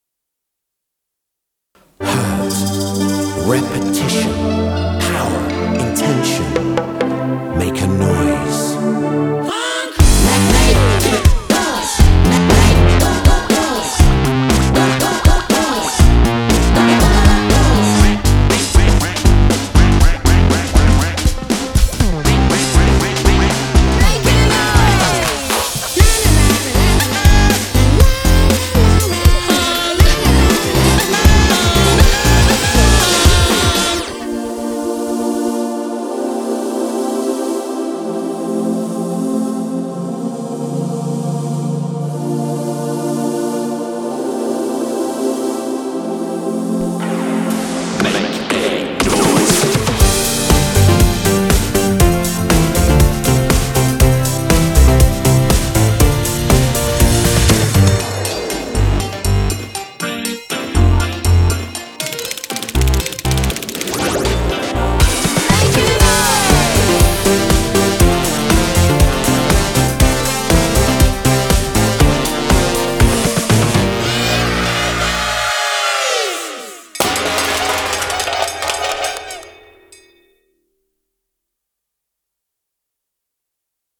instrumental track